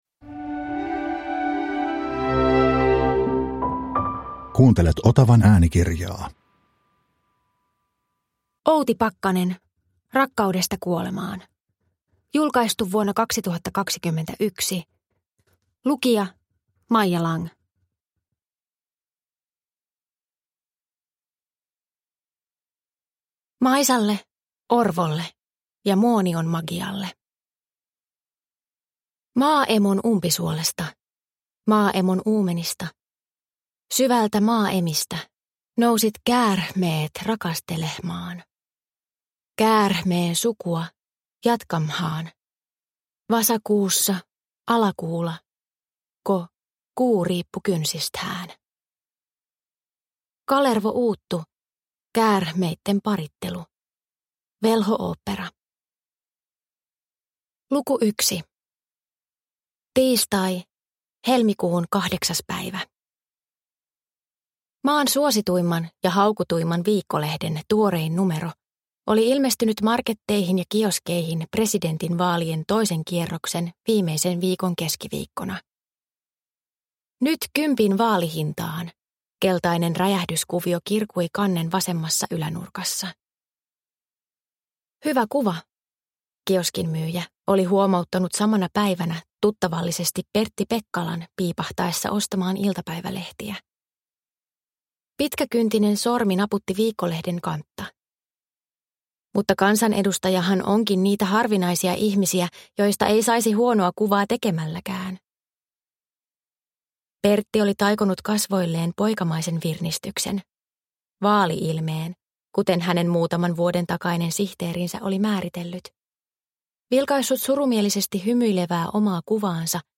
Rakkaudesta kuolemaan – Ljudbok – Laddas ner